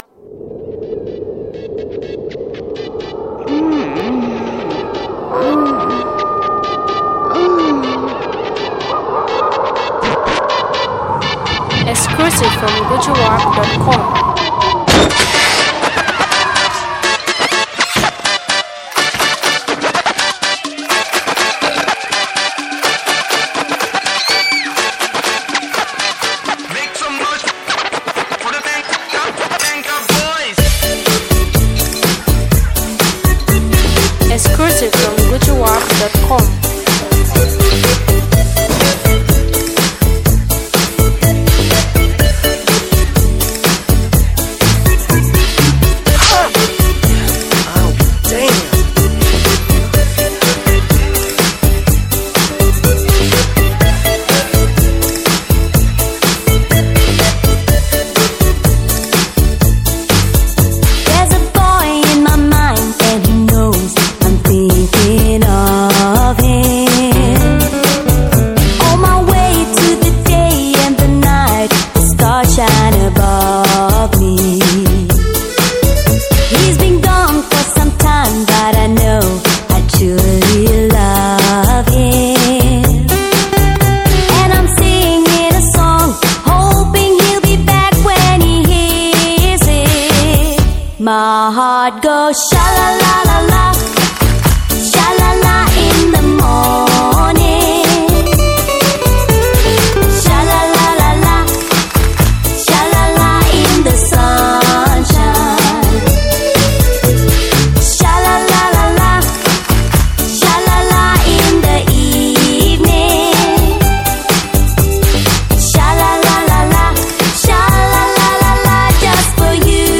are iconic stars of the Dutch Eurodance scene
renowned for their infectious energy and catchy beats
upbeat anthem